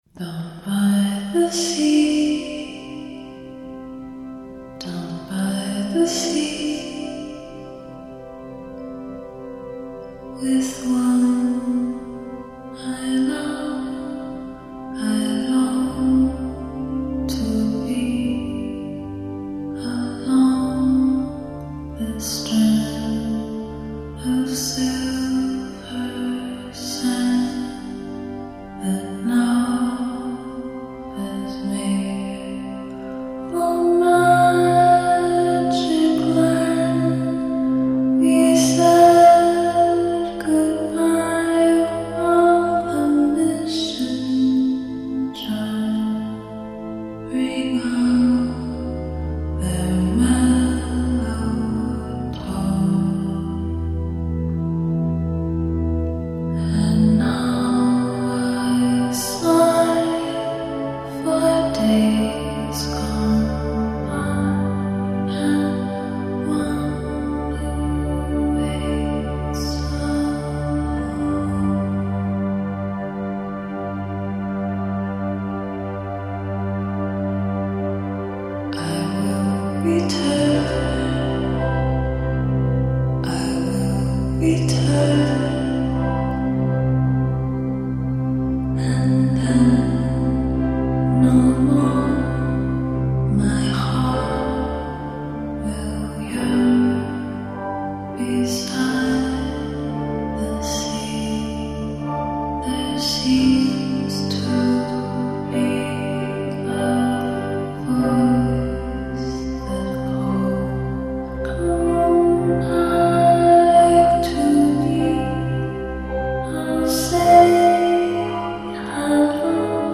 Performed by Julia Holter.